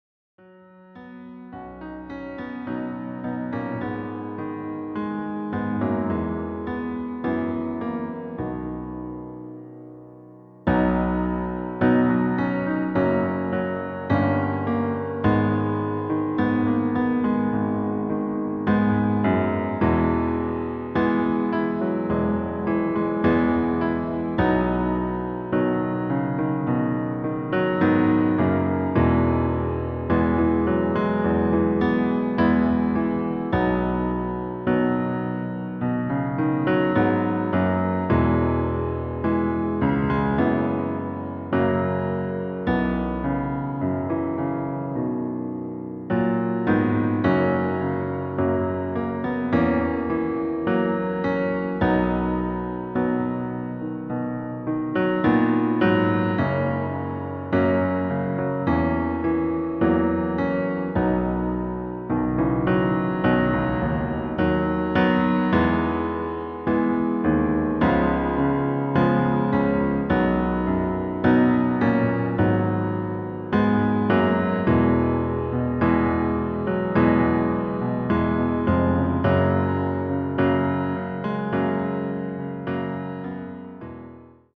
• Tonart: Cis, D, F, H
• Das Instrumental beinhaltet NICHT die Leadstimme
Klavier / Streicher